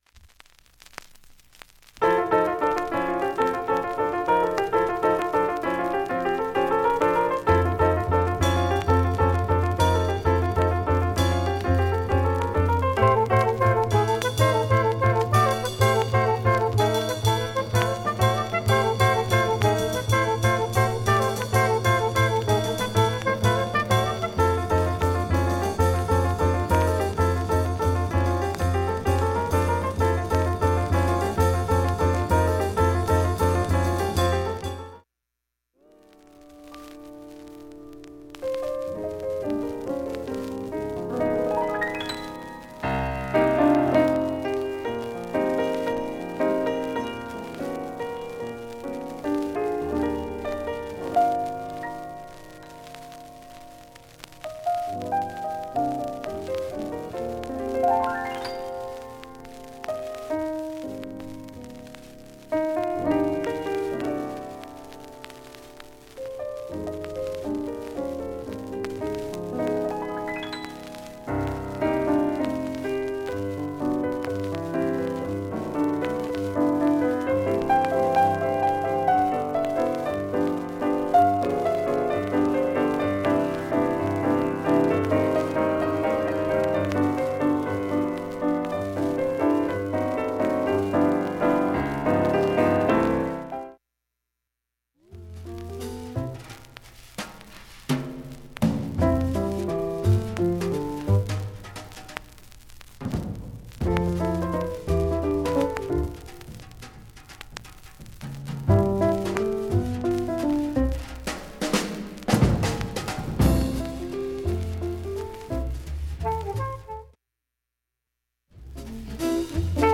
静かな部でかすかにチリプツ出ますが
ごくかすかなレベルです。
B-1序盤にかすかなプツが９回出ます。
単発のかすかなプツが４箇所